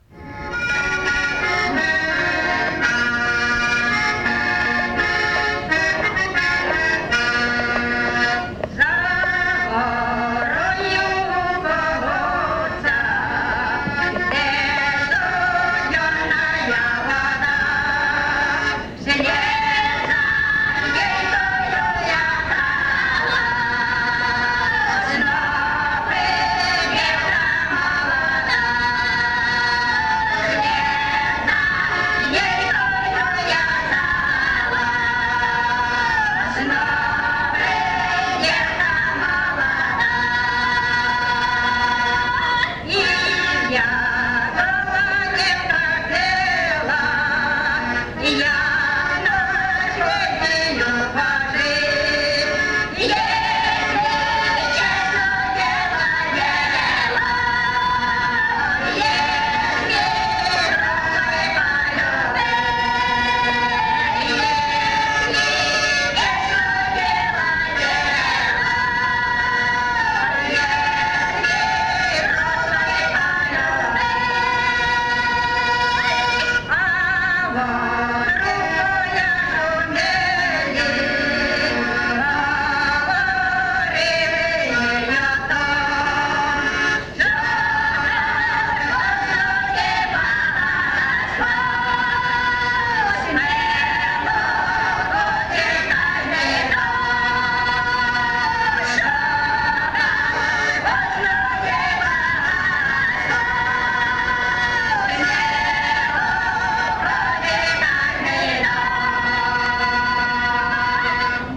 ЖанрПісні з особистого та родинного життя, Сучасні пісні та новотвори
Місце записус. Олександрівка, Валківський район, Харківська обл., Україна, Слобожанщина